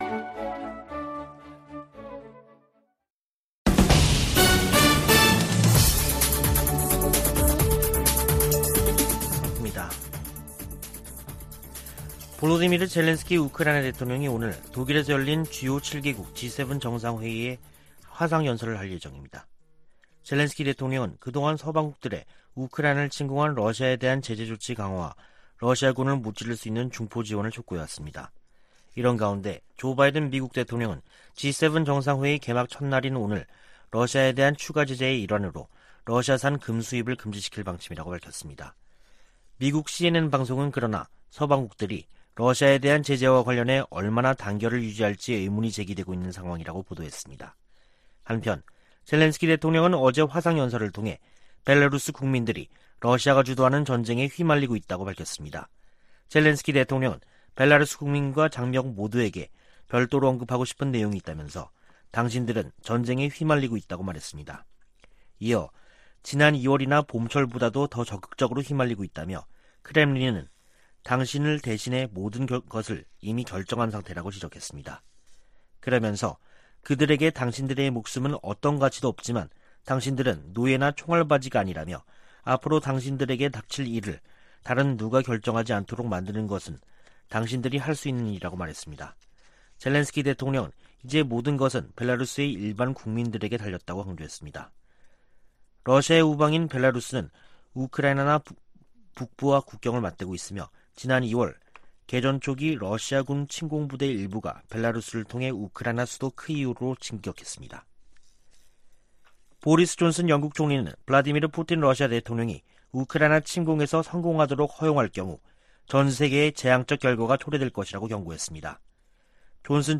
VOA 한국어 간판 뉴스 프로그램 '뉴스 투데이', 2022년 6월 27일 2부 방송입니다. 권영세 한국 통일부 장관은 북한이 7차 핵실험을 할 경우 엄청난 비판에 직면할 것이라고 경고했습니다. 미국 의회 산하 위원회가 한국의 난민정책에 관한 청문회에서 문재인 정부에 의한 탈북 어민 강제북송을 비판했습니다. 알래스카 미군 기지가 북한의 미사일 위협을 24시간 감시하고 있다고 강조했습니다.